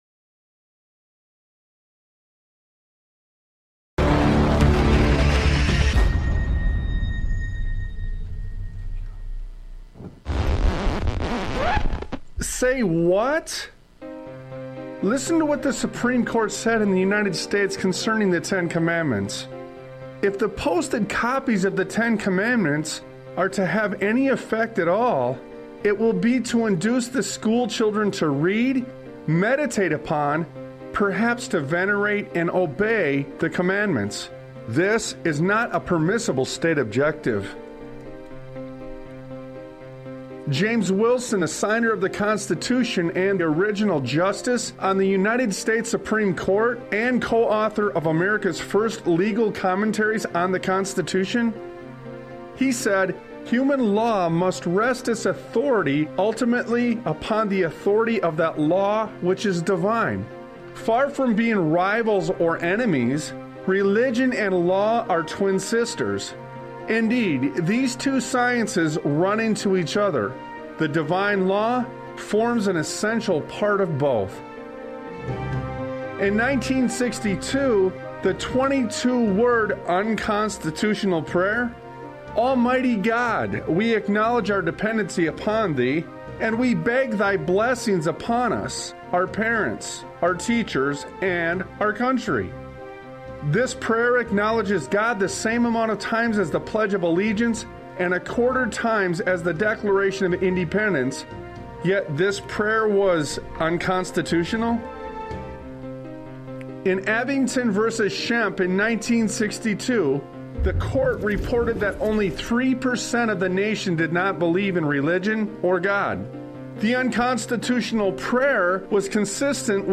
Talk Show Episode, Audio Podcast, Sons of Liberty Radio and Have You Noticed on , show guests , about Have You Noticed, categorized as Education,History,Military,News,Politics & Government,Religion,Christianity,Society and Culture,Theory & Conspiracy